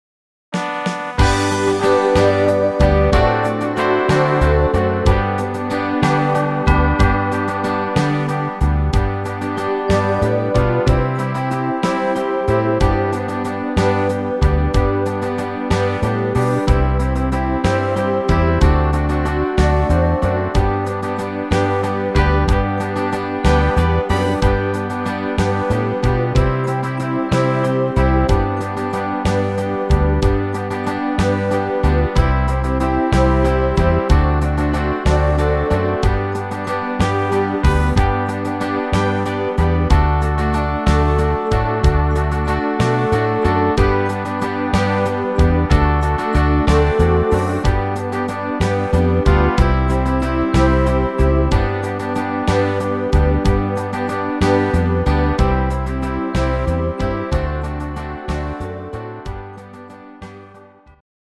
im 6/8 Takt